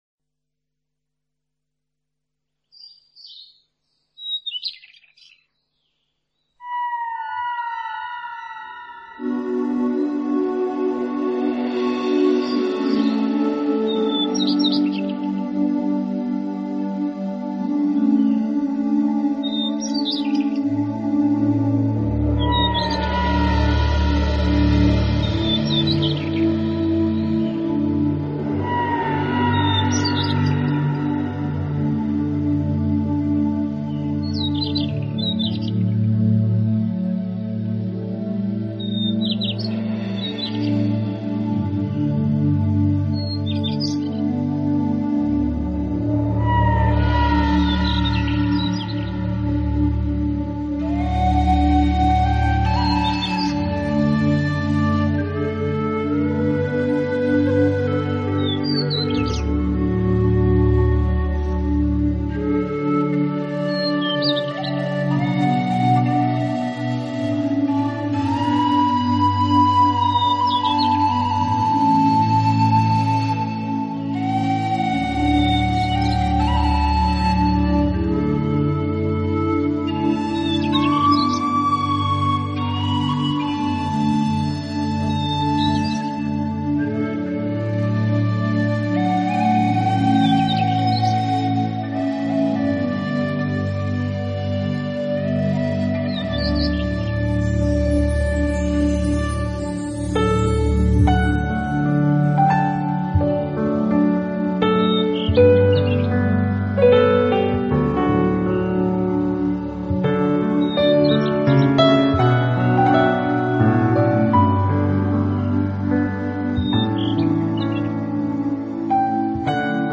【纯乐钢琴】
星月照耀的美丽夜晚，开场与乐曲进行间鸟啼展翅声清晰可辩；排笛带出清冷深之感；而温
暖的钢琴引入，感觉夜的平安字根表中蕴藏着无限活力。